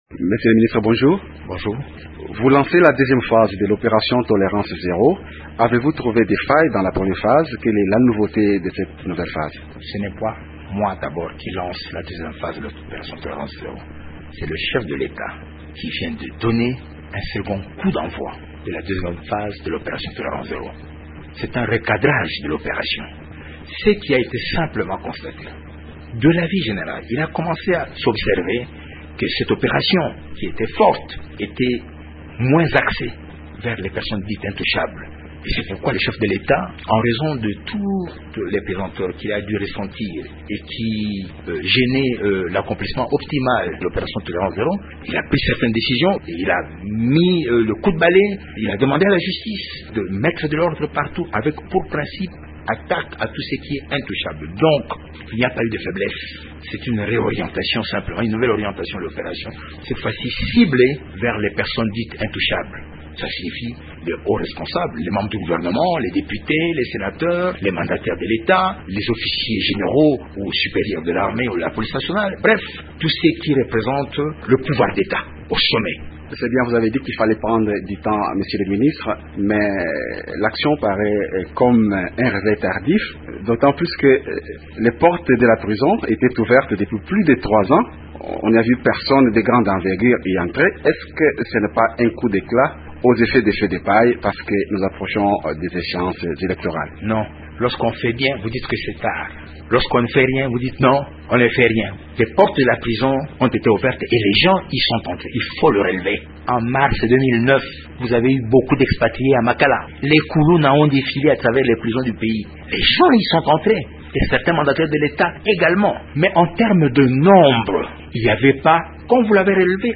Le ministre de la Justice, Luzolo Bambi a annoncé, samedi 12 mars à Kinshasa, la deuxième phase de l’opération « tolérance zéro » pour traduire en justice les hauts fonctionnaires de l’Etat suspectés de corruption ou de détournement des deniers publics.